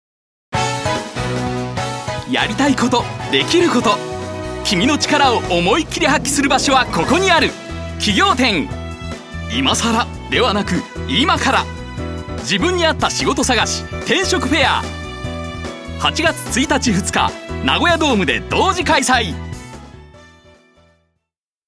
ハイトーンナレーション